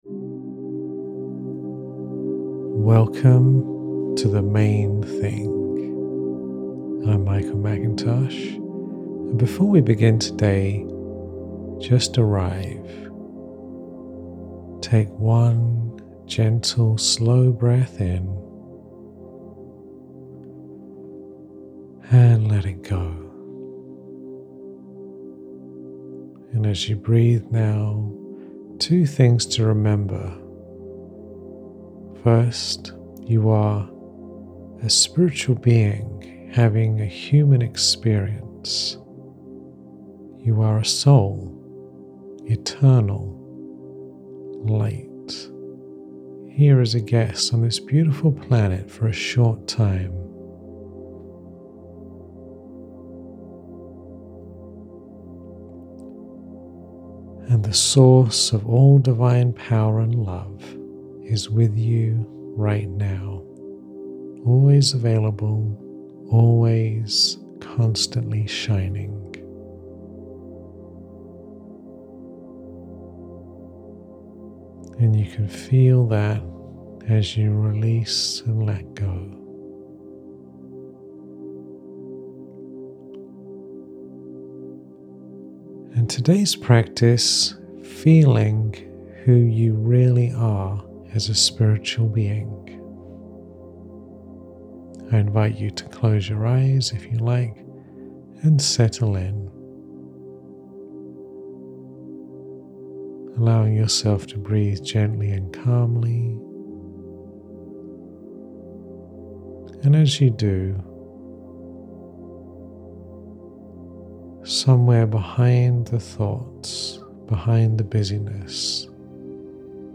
I Am A Soul – Higher Self Meditation
This guided meditation invites you to settle into the deepest truth of who you really are — a soul, a point of eternal light sitting right behind your eyes, here on this planet as a guest for a short time. You will visualise that light clearly, feel the stillness of your true nature in your body, and rest in the silence that exists beneath all thought and worry.